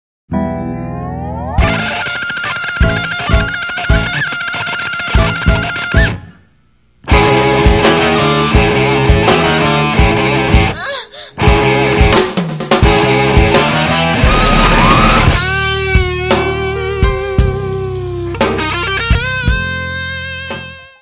saxofone alto, clarinete
bateria
guitarra
teclados
Área:  Novas Linguagens Musicais